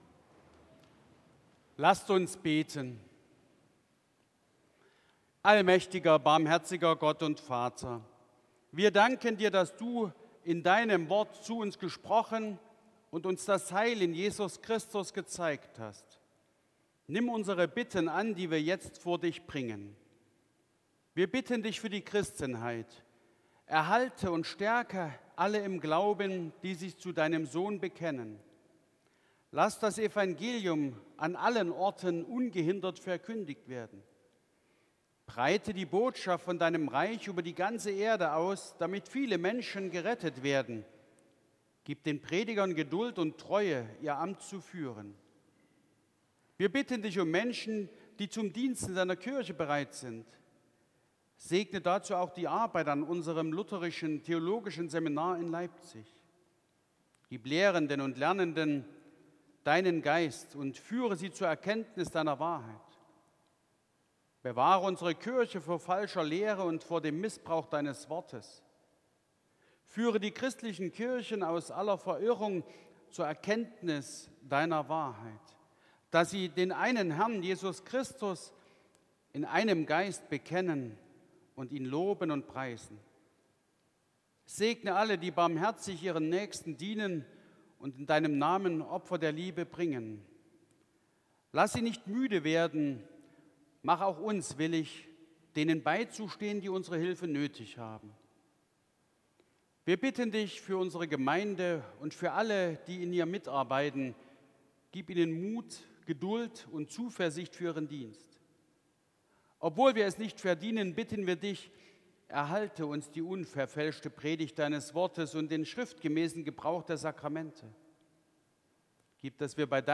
Gottesdienst am 23.02.2025
Gebet, Vaterunser, Entlassung und Segen Ev.-Luth.